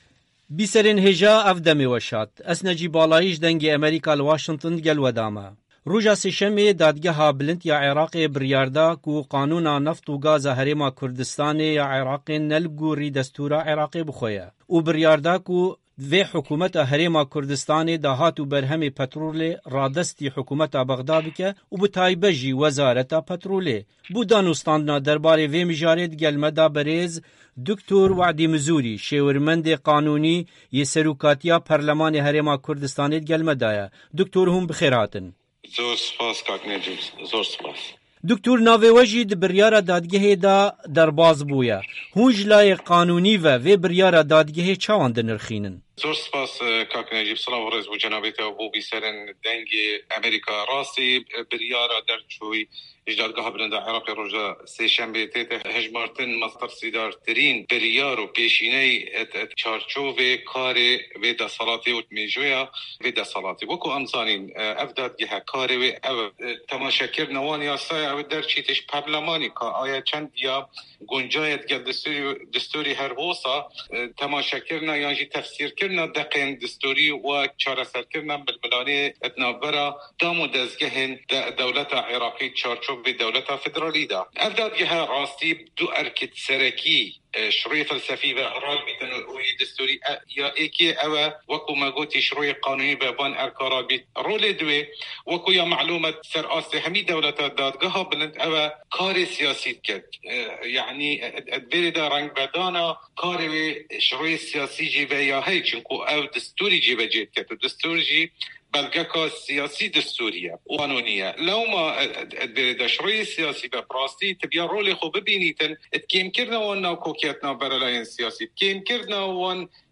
Hevpeyvîn